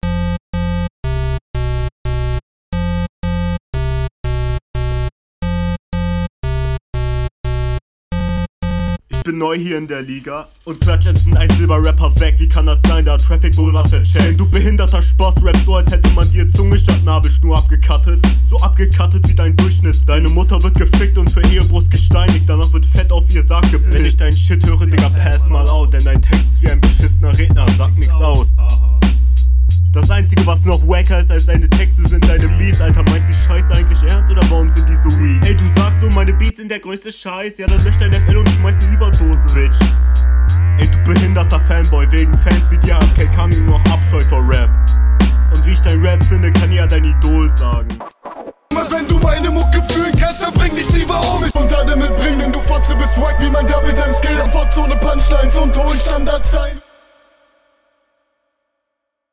stimme und stimmlage dope, geiler beat, quali geht besser und auch taktgefühl
Am Anfang war der Flow stabil, ab dem ersten Switch hatte das nicht mehr sonderlich …